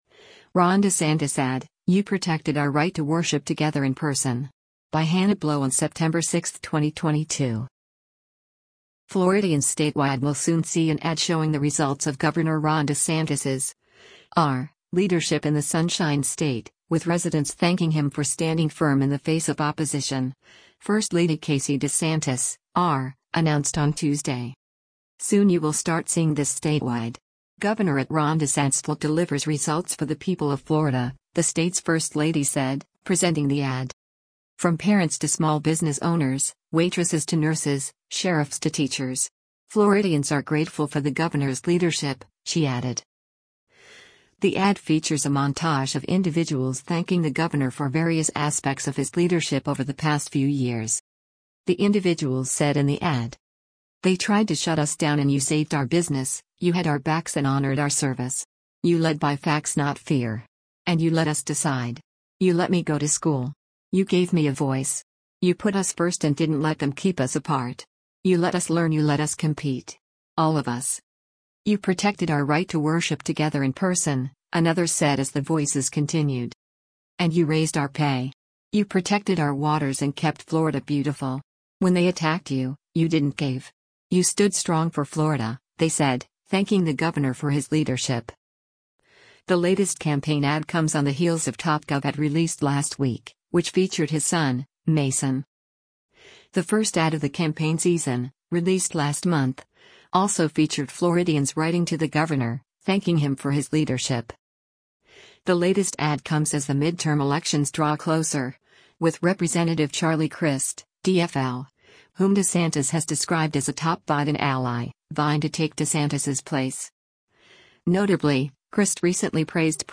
Ad for Ron DeSantis Florida Governor
The ad features a montage of individuals thanking the governor for various aspects of his leadership over the past few years.
“You protected our right to worship together in person,” another said as the voices continued,